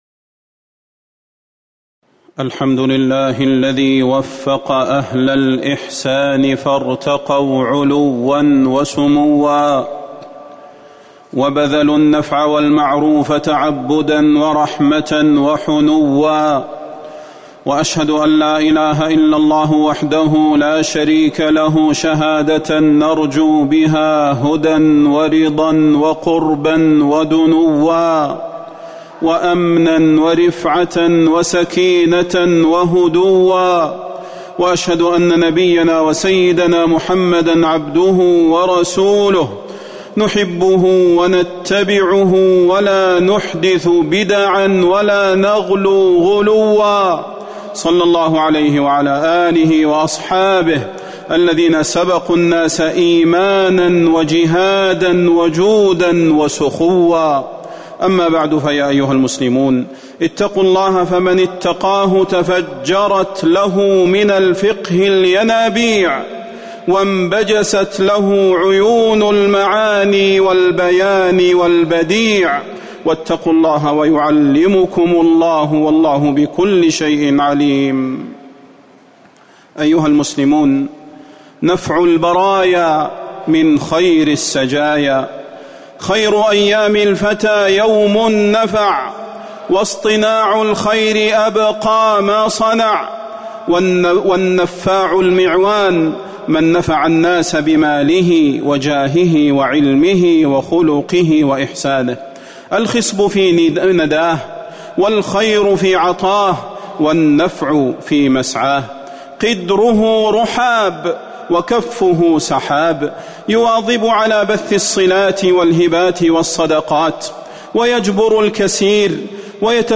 تاريخ النشر ١٢ صفر ١٤٤١ هـ المكان: المسجد النبوي الشيخ: فضيلة الشيخ د. صلاح بن محمد البدير فضيلة الشيخ د. صلاح بن محمد البدير نفع البرايا من خير السجايا